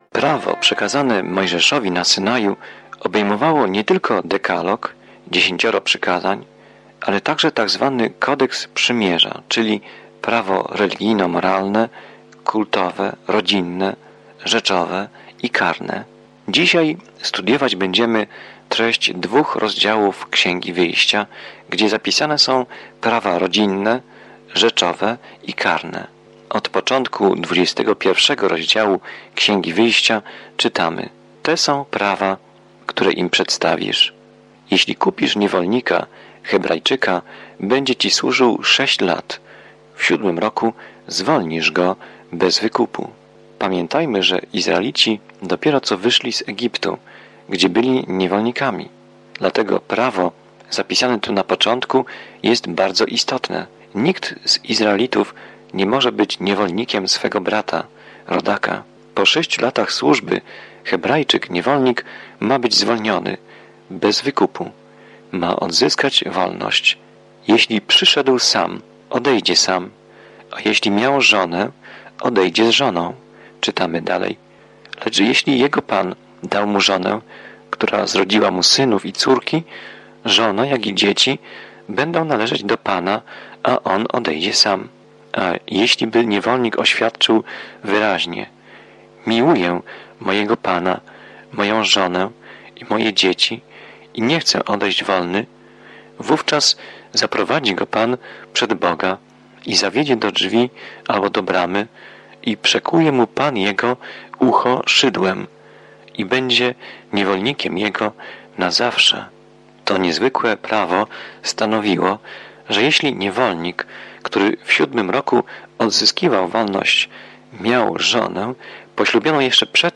Pismo Święte Wyjścia 21 Wyjścia 22:1-30 Dzień 14 Rozpocznij ten plan Dzień 16 O tym planie Exodus śledzi ucieczkę Izraela z niewoli w Egipcie i opisuje wszystko, co wydarzyło się po drodze. Codzienna podróż przez Exodus, słuchanie studium audio i czytanie wybranych wersetów słowa Bożego.